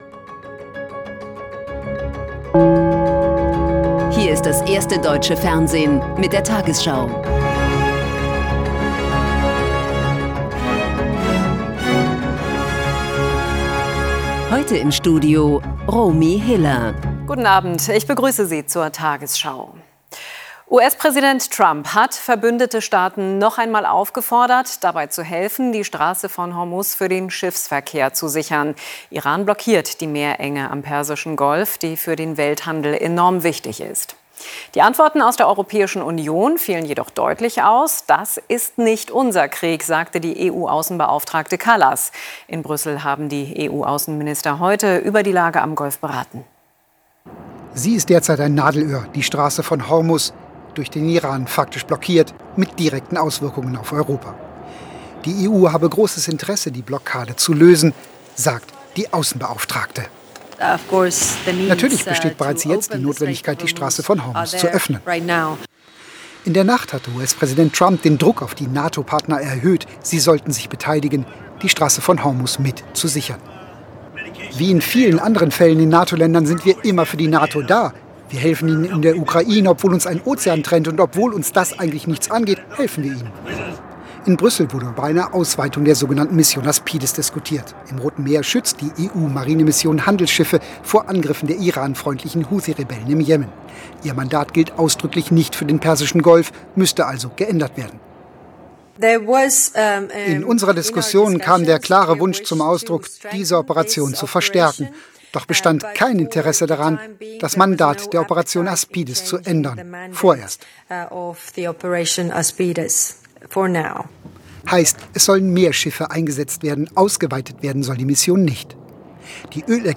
tagesschau: Die 20 Uhr Nachrichten (Audio)